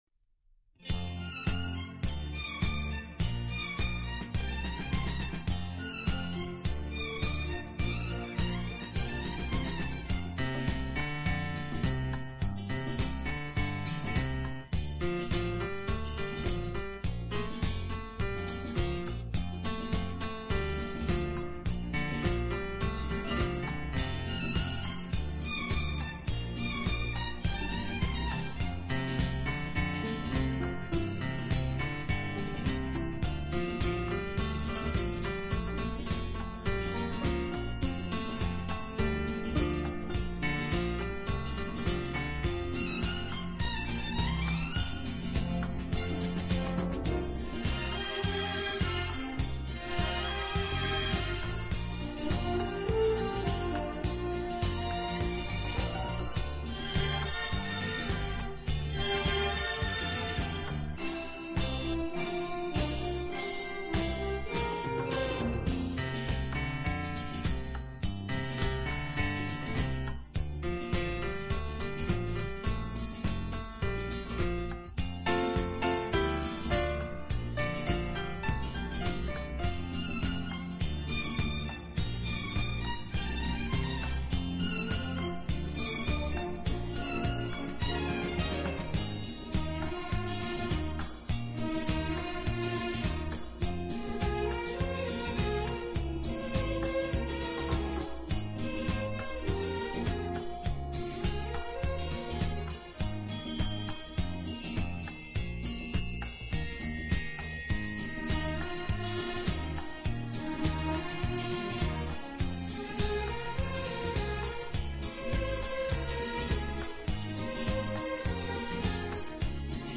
勾勒出华丽而精致的背景，虽然间或也会采用打击乐。